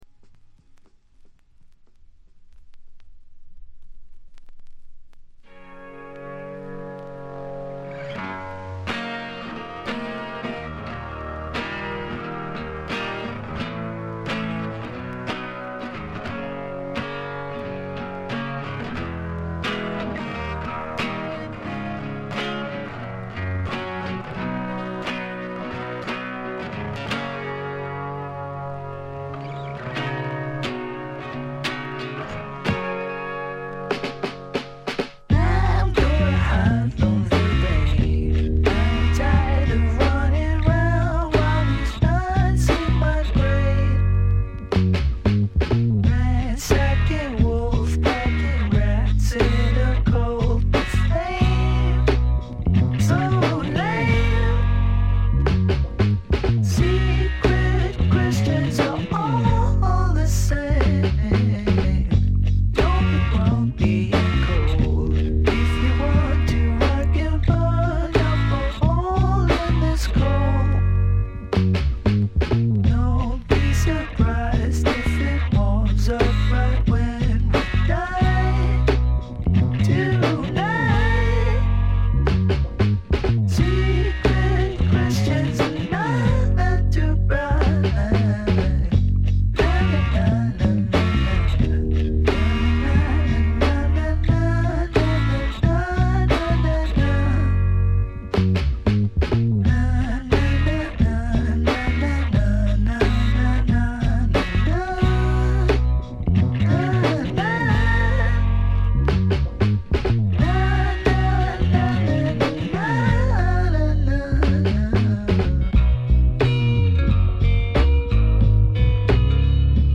試聴曲は現品からの取り込み音源です。
Drums